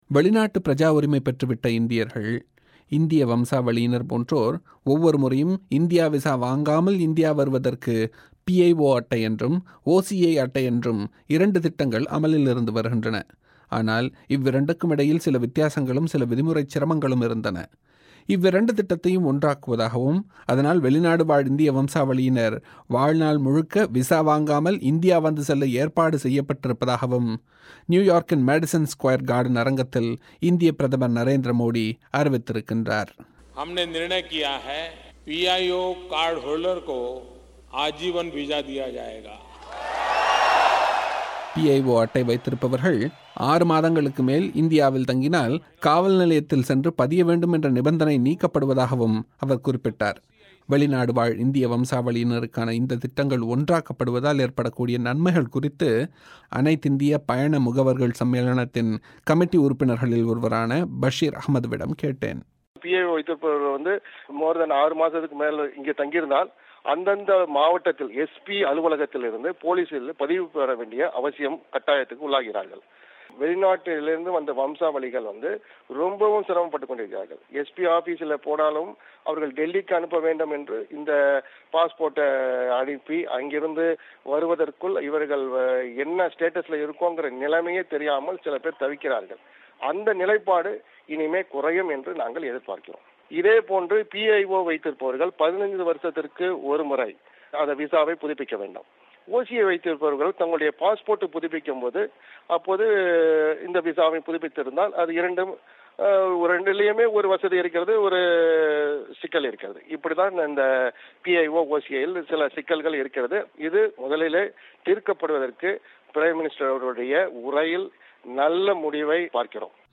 செய்திக் குறிப்பை நேயர்கள் இங்கு கேட்கலாம்.